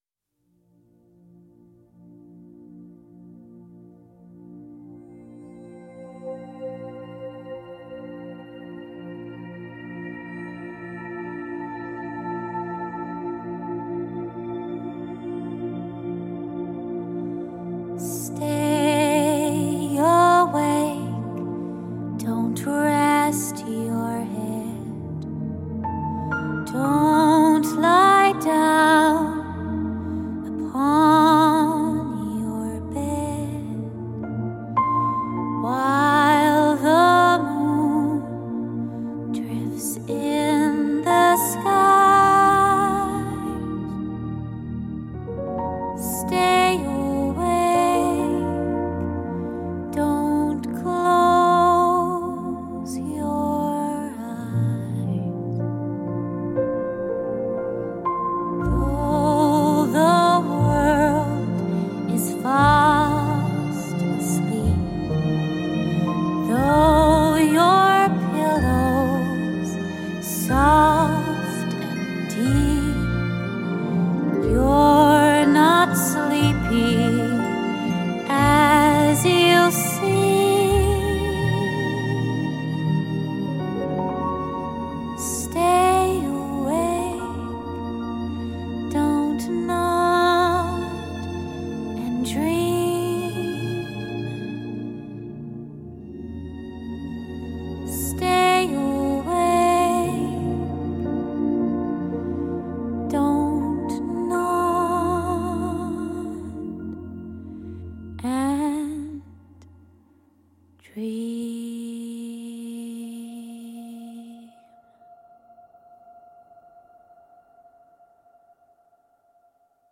管弦乐、吉他、长笛和温暖的歌词给人一种舒缓的感觉